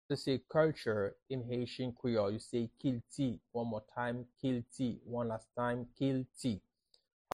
How to say “Culture” in Haitian Creole – “Kilti” pronunciation by a native Haitian teacher
“Kilti” Pronunciation in Haitian Creole by a native Haitian can be heard in the audio here or in the video below:
How-to-say-Culture-in-Haitian-Creole-–-Kilti-pronunciation-by-a-native-Haitian-teacher.mp3